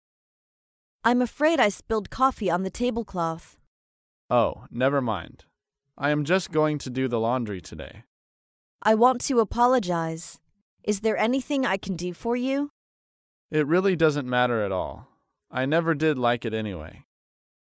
在线英语听力室高频英语口语对话 第115期:弄脏物品致歉的听力文件下载,《高频英语口语对话》栏目包含了日常生活中经常使用的英语情景对话，是学习英语口语，能够帮助英语爱好者在听英语对话的过程中，积累英语口语习语知识，提高英语听说水平，并通过栏目中的中英文字幕和音频MP3文件，提高英语语感。